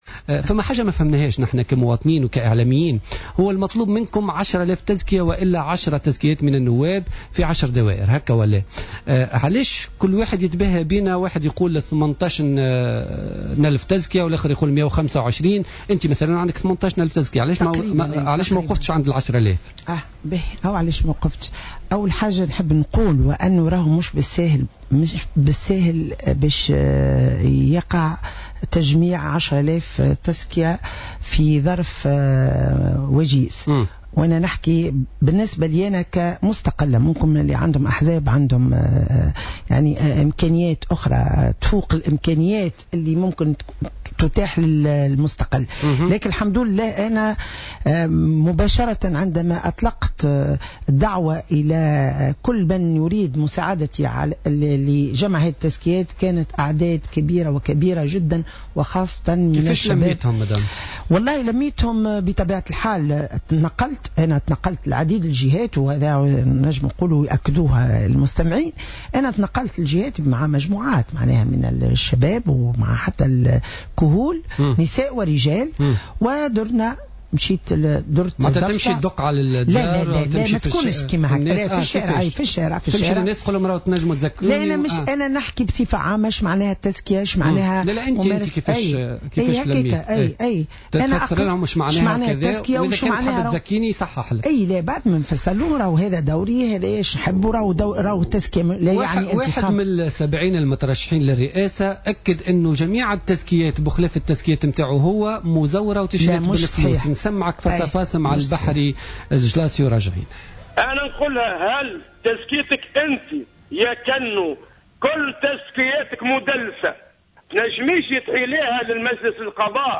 رفضت القاضية كلثوم كنو خلال مداخلة لها في برنامج "بوليتيكا" الردّ على الاتهامات التي وجّهها لها رئيس حزب الانفتاح والوفاء البحري الجلاصي بخصوص تدليس التزكيات التي جمعتها للانتخابات الرئاسية القادمة.